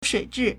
水质 (水質) shuǐzhì
shui3zhi4.mp3